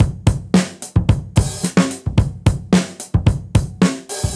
Index of /musicradar/dusty-funk-samples/Beats/110bpm
DF_BeatC_110-04.wav